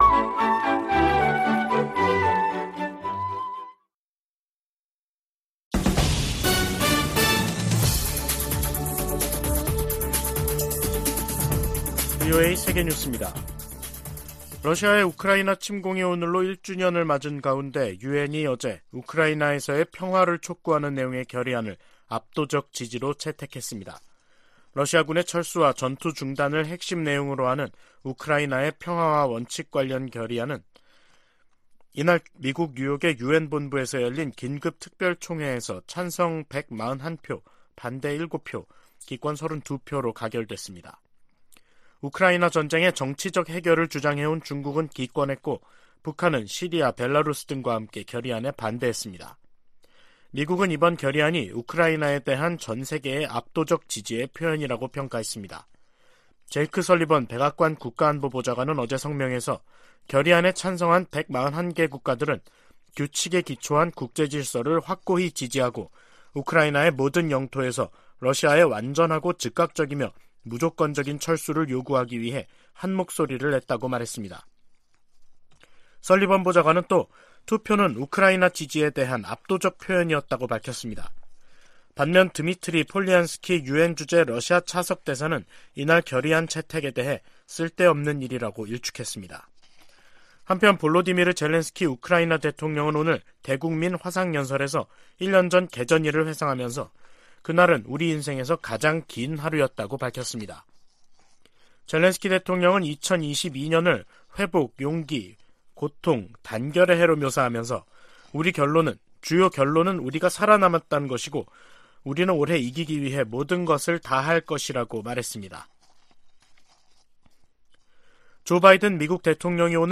VOA 한국어 간판 뉴스 프로그램 '뉴스 투데이', 2023년 2월 24일 2부 방송입니다. 북한은 미국이 연합훈련 등 적대적이며 도발적인 관행을 계속 이어가면 선전포고로 간주될 수 있다고 밝혔습니다. 미 국방부는 미한 두 나라가 22일 펜타곤에서 제8차 양국 확장억제 운용연습(DSC TTX)을 실시했다고 밝혔습니다. 미 민주당의 브래드 셔먼 하원의원이 한국전쟁 종전선언을 비롯한 ‘한반도 평화’ 조치를 담은 법안을 다음 주 재발의할 예정입니다.